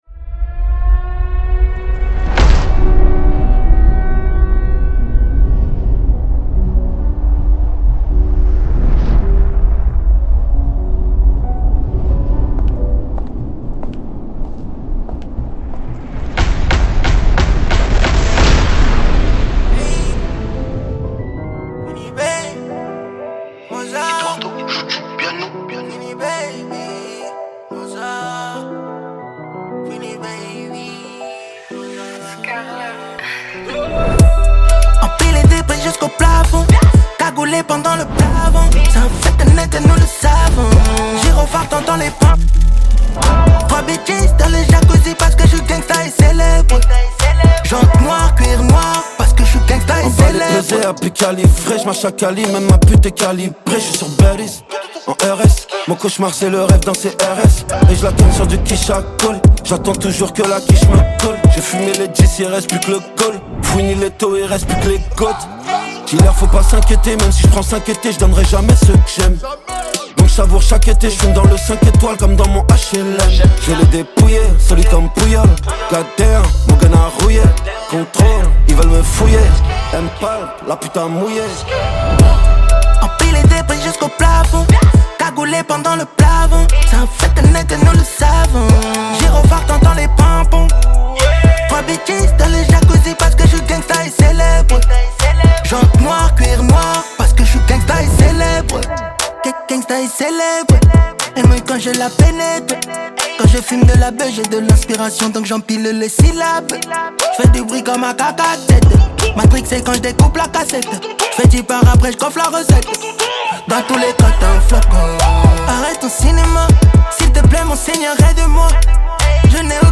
french rap, french r&b Écouter sur Spotify